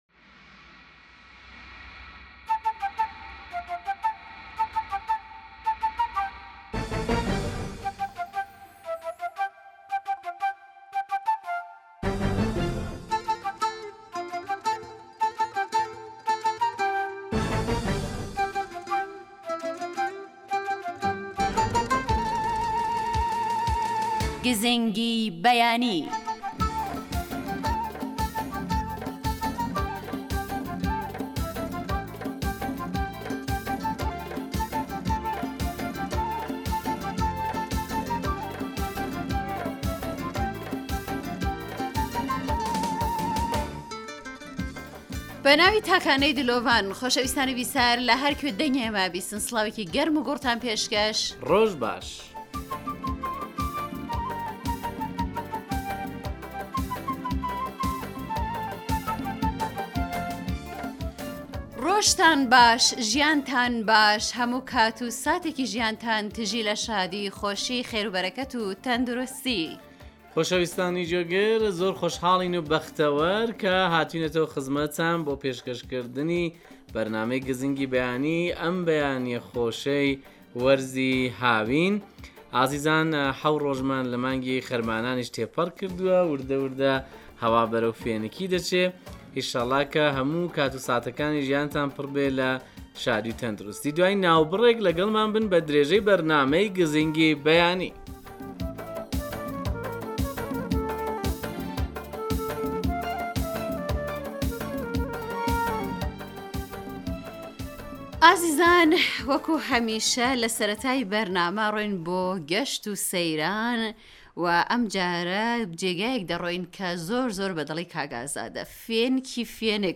گزینگی بەیانی بەرنامەیێكی تایبەتی بەیانانە كە هەموو ڕۆژێك لە ڕادیۆ كەردی تاران بڵاو دەبێتەوە و بریتییە لە ڕاپۆرت و دەنگی گوێگران و تاووتوێ كردنی بابەتێكی پزیشكی.